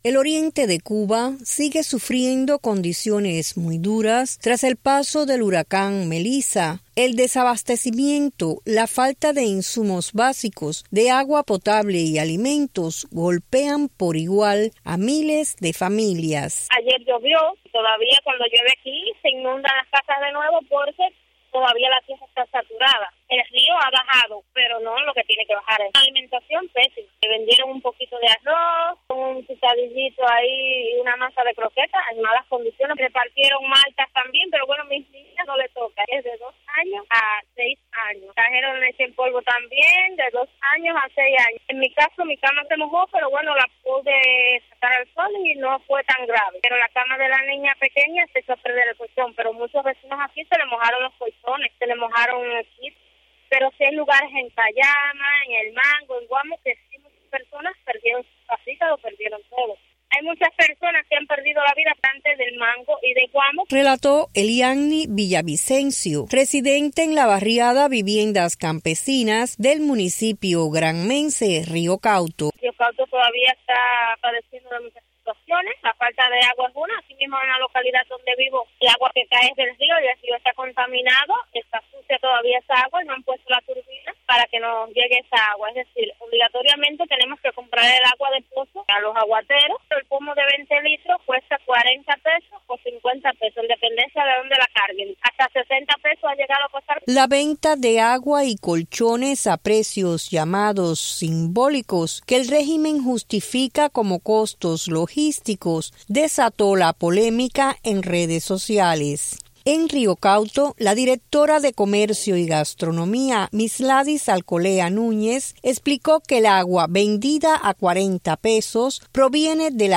Una residente de ese municipio cuenta en qué condiciones está la mayoría de los afectados, dos semanas después del paso del huracán Melissa.